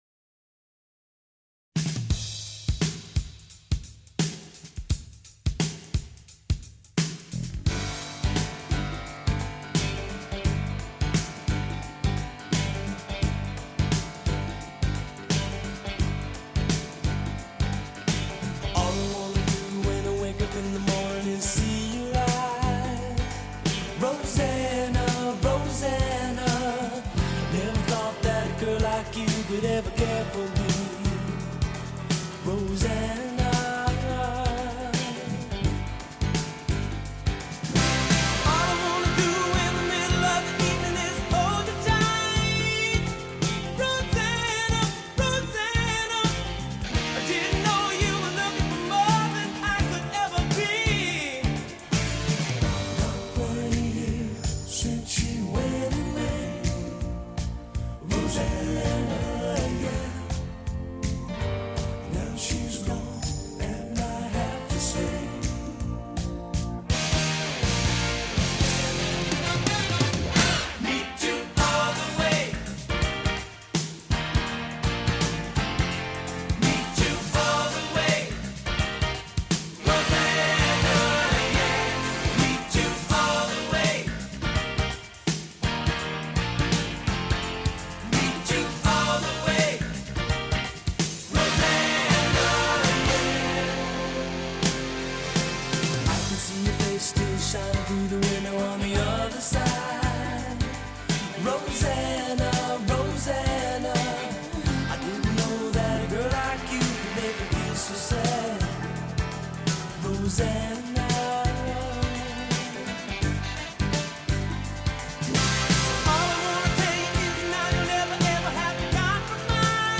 Hør en times intervju